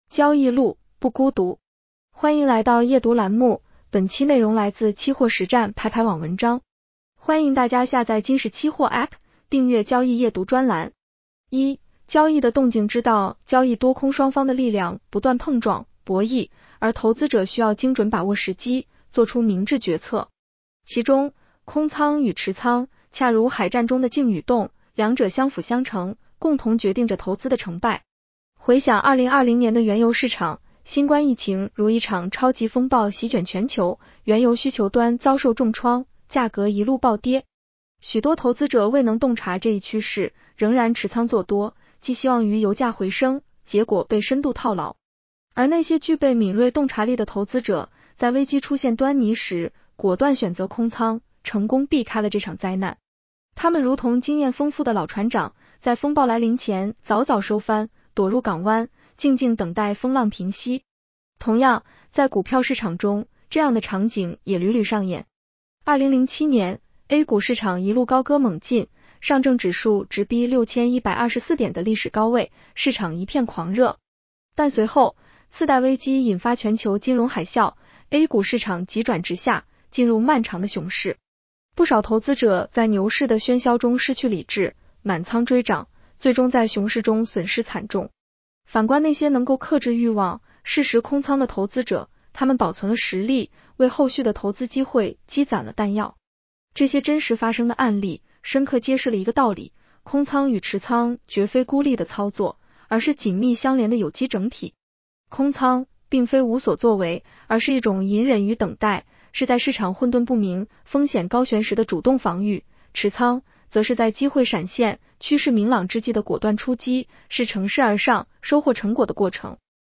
女声普通话版 下载mp3 一、交易的 “动静” 之道 交易多空双方的力量不断碰撞、博弈，而投资者需要精准把握时机，做出明智决策。